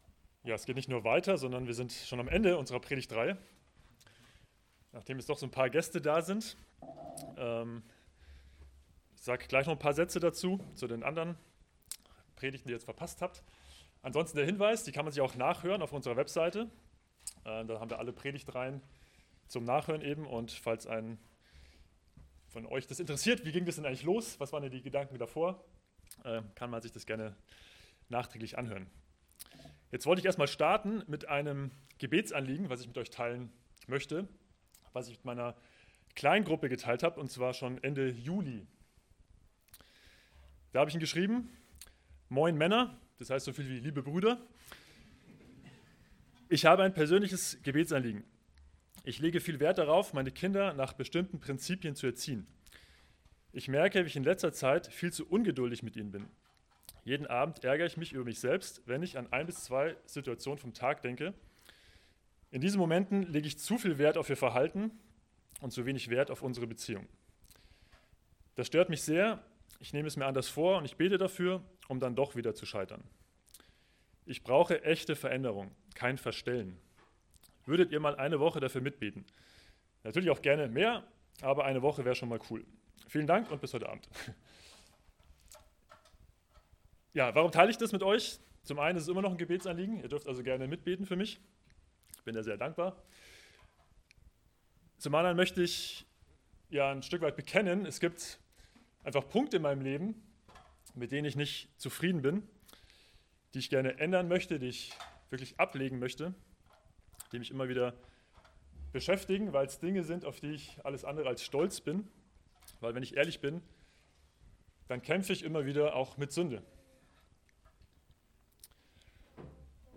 Aus der Predigtreihe: "Vertrau mir"